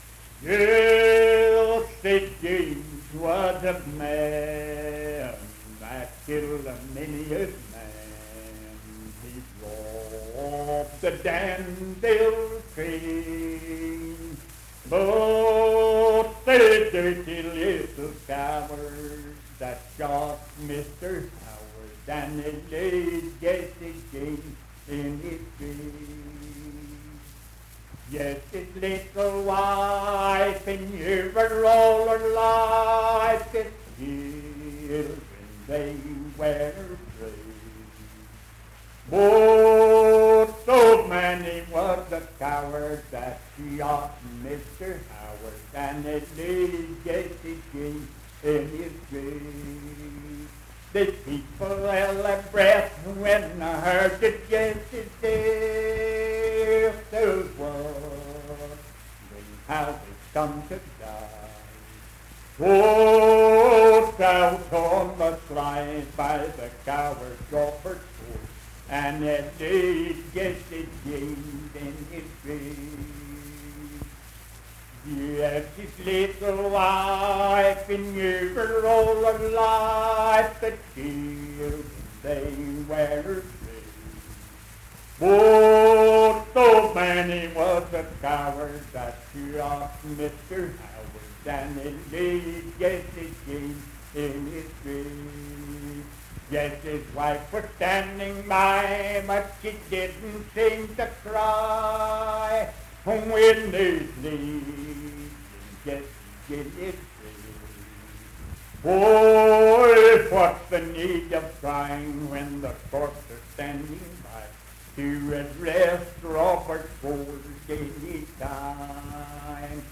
Unaccompanied vocal music performance
Verse-refrain 3(4) & R(4).
Voice (sung)
Nicholas County (W. Va.)